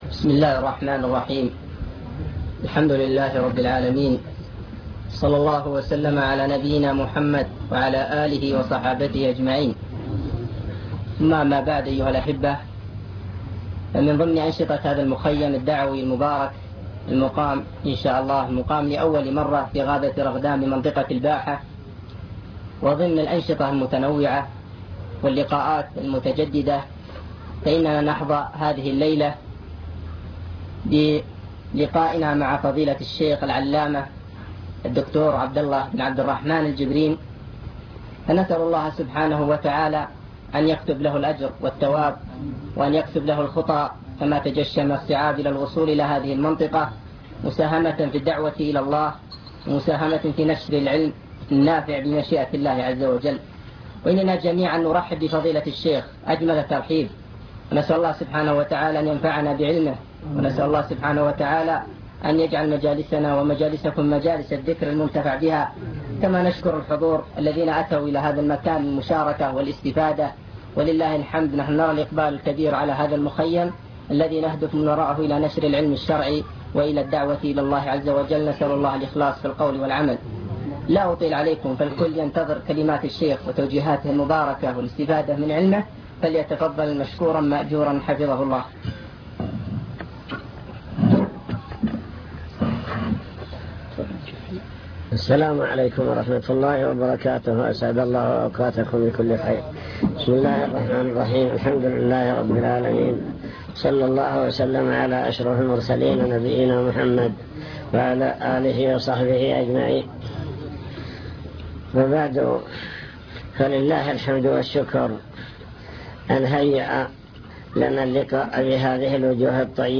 المكتبة الصوتية  تسجيلات - محاضرات ودروس  محاضرات عن طلب العلم وفضل العلماء بحث في: أهم المسائل التي يجب على المسلم تعلمها